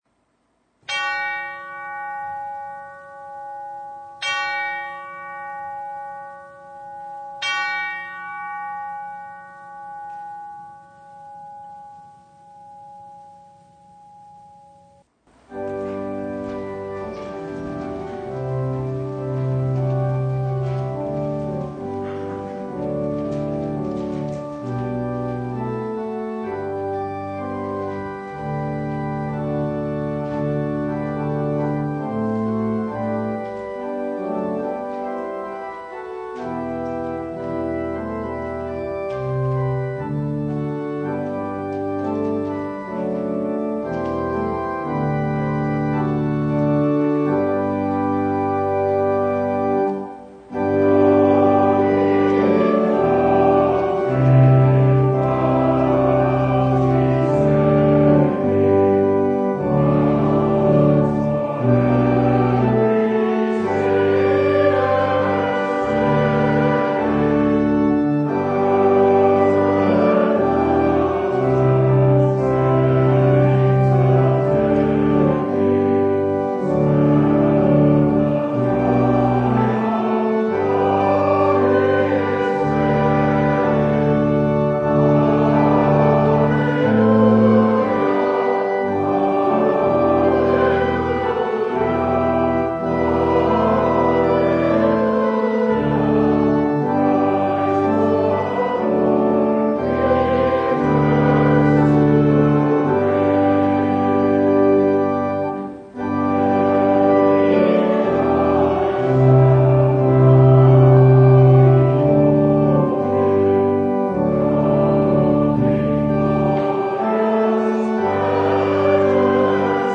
Service Type: Advent